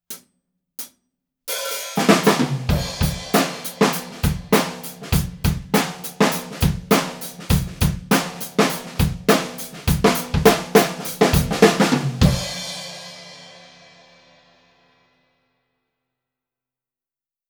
ドラムセットは、Canopus yaiba kit
すべて、EQはしていません。
①　アンビエンス　約２ｍ
まずはじめに、ドラムから約２ｍのところにマイキングしました。
高さはシンバルより少し高い位置ですね！
NT2Aの特徴でもあるシャキッとした音質ですね！